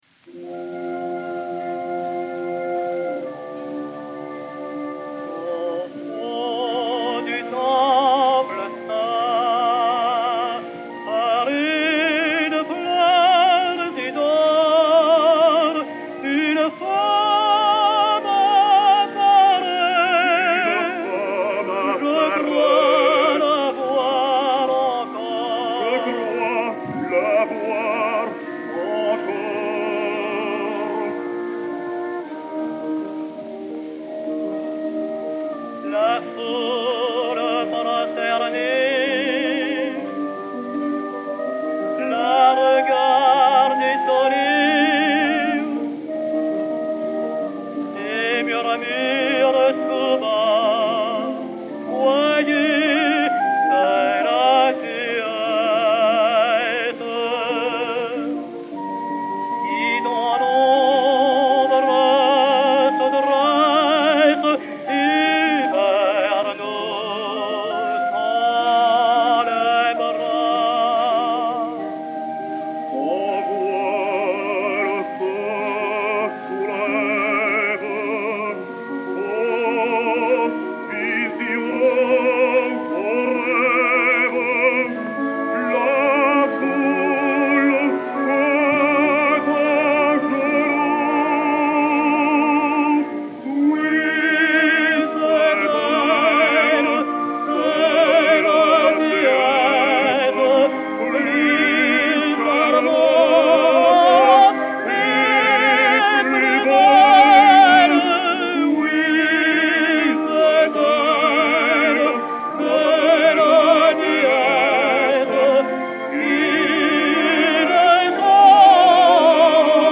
C-11470, enr. à Camden, New Jersey, le 18 janvier 1912